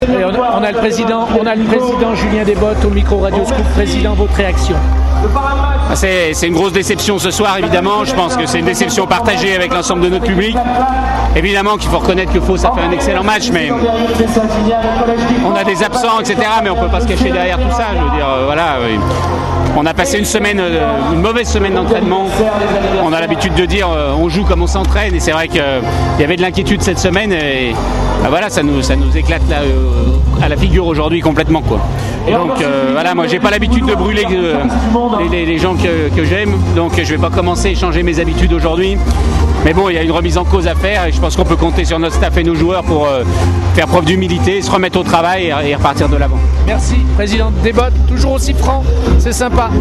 Les interviews